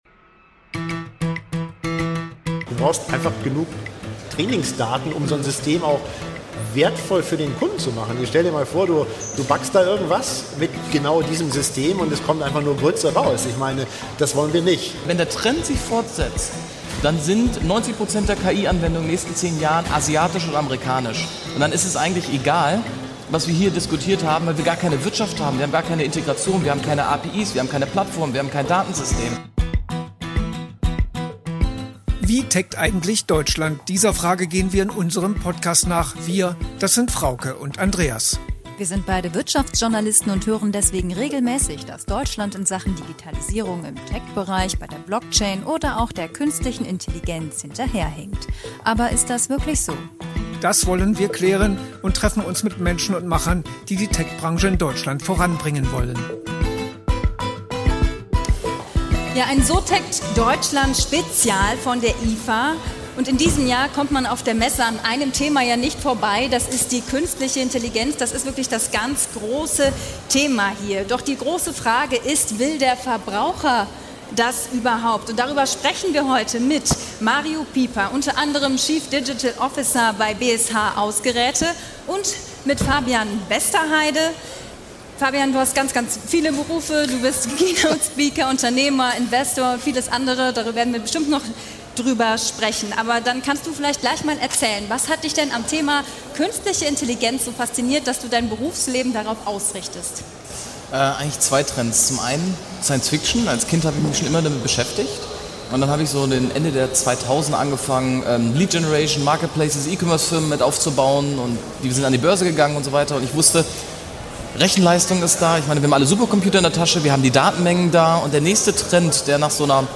Brauchen wir denkende Backöfen? Live von der IFA in Berlin ~ So techt Deutschland - der Tech-Podcast Podcast
Die Internationale Funkausstellung (IFA) ist ein Highlight für alle Technik-Freunde. Auch "So techt Deutschland" ist auf dem Messegelände in Berlin unterwegs und hat am Set von n-tv vor Publikum über Sinn und Unsinn von Künstlicher Intelligenz diskutiert....